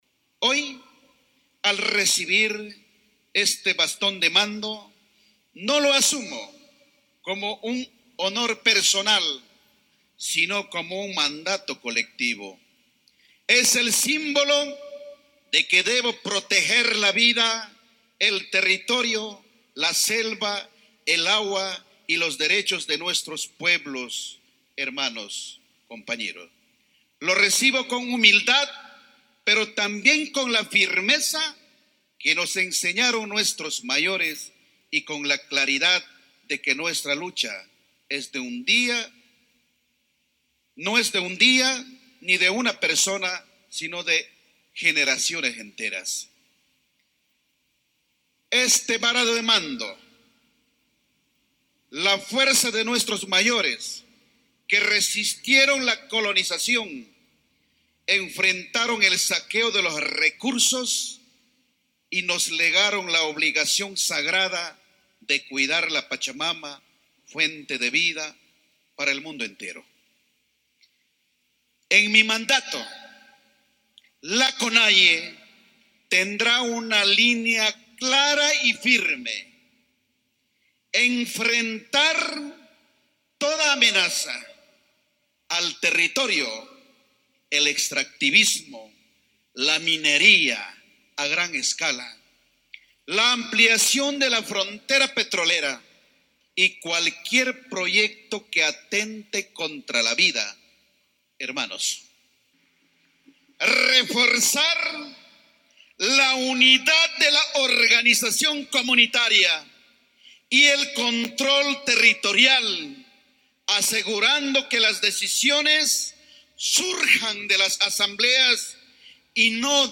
Puyo fue la sede a donde se dieron cita más de mil personas de distintas provincias del Ecuador.
Luego de los actos simbólicos y posesión de los nuevos dirigentes indígenas, llegó el turno de los discursos donde Marlon Vargas al recibir el bastón de mando lanzó mensajes elocuentes y firmes al gobierno de Daniel Noboa.
MARLON-VARGAS-DISCURSO-POSESION.mp3